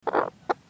Sound Effects
Short Chicken Noise